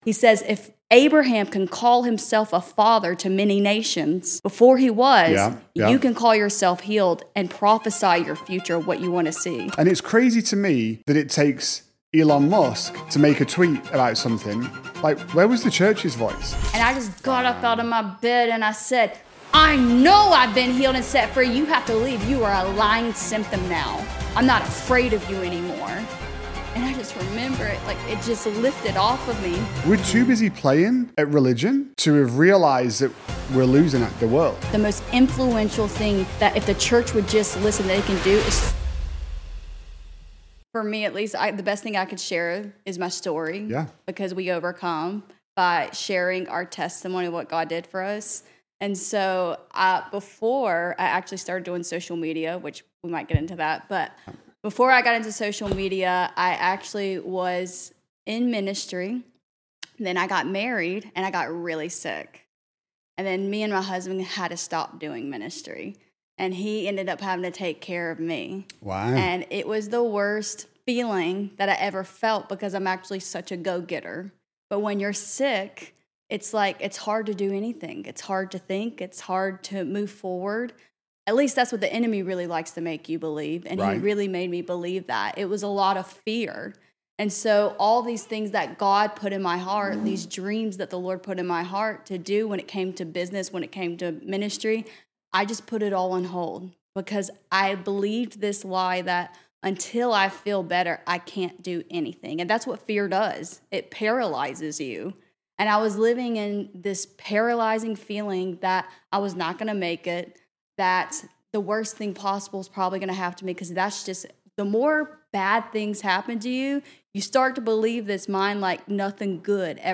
Just bold conversations about faith, entrepreneurship, generosity, leadership, and legacy… with real business owners who love Jesus and want their work to mean more.